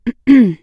throat2.ogg